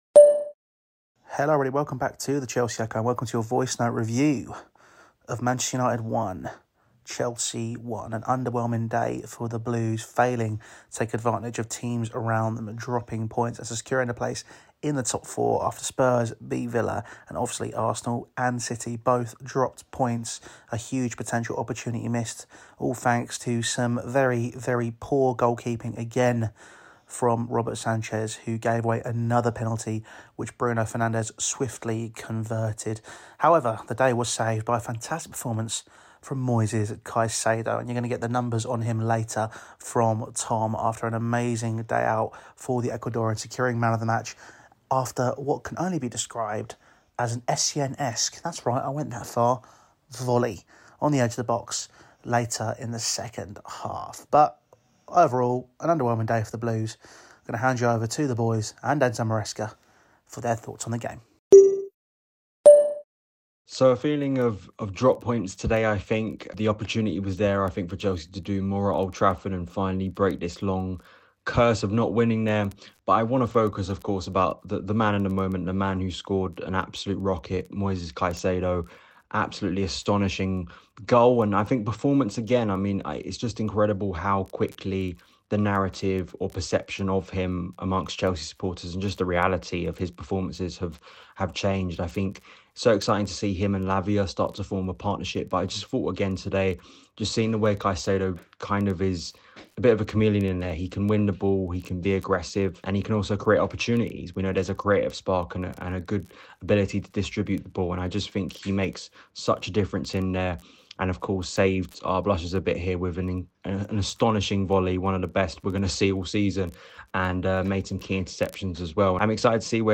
Chelsea drop three points at Old Trafford and the wait for a win there stretches to 11 years, but out of the witchcraft that Manchester United had, Moises Caicedo announced himself fully for all the right reasons in the 1-1 draw. Here, is your voicenote review...